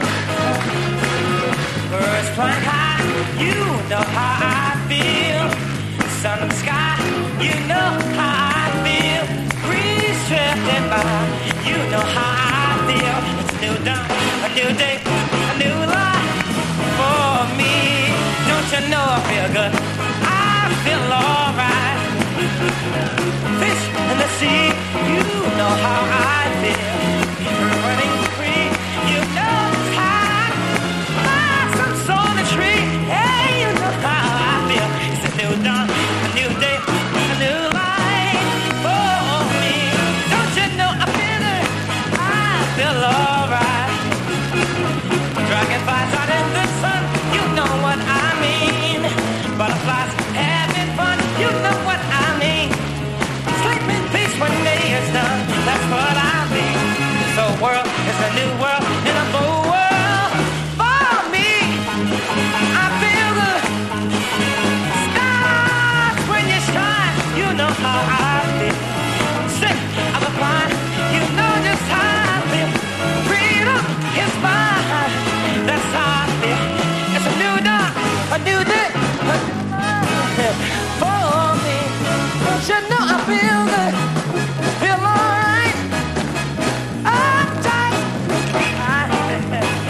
1968年2月13日東京渋谷公会堂での実況録音！